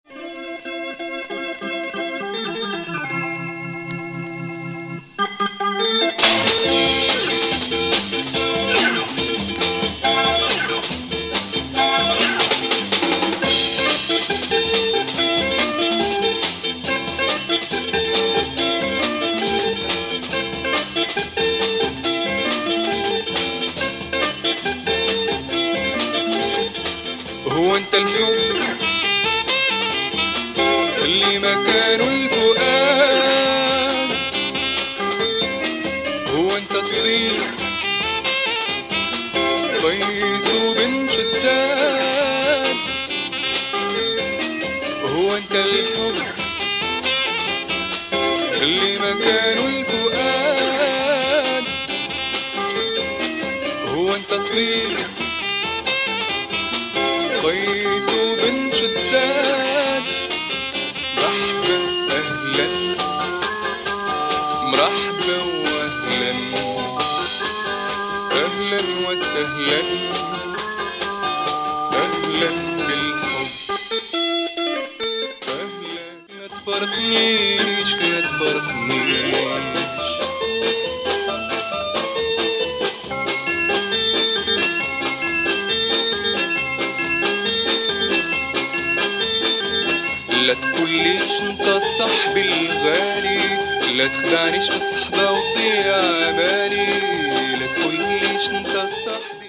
Moroccan soul 45, terribly rare.